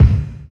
NY 14 BD.wav